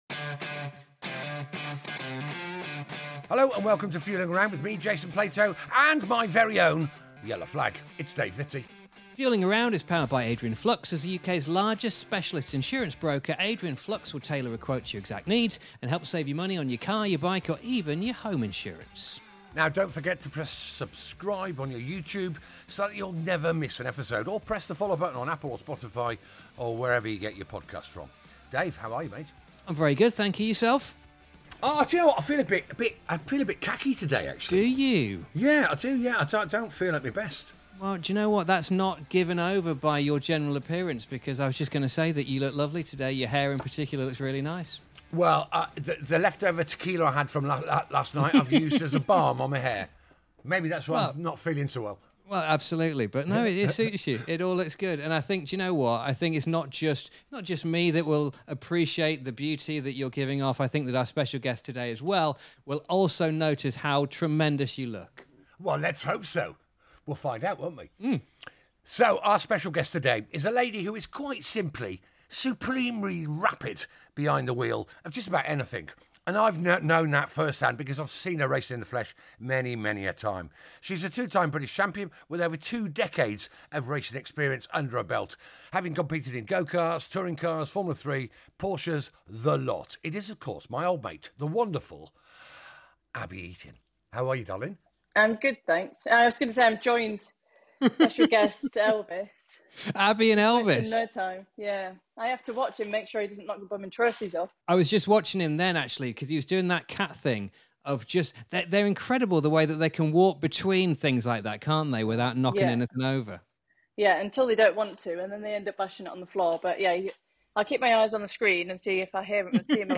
In addition to the trophy cabinet, Abbie was selected to be the test driver on 'The Grand Tour' so has lived the dream of every car fan, and has the stories from those infamous trips to prove it! Ably assisted by her cat Elvis, this is a brilliant chat about a racing career that goes from strength to strength, even though Abbie has certainly had her fair share of hurdles to overcome along the way!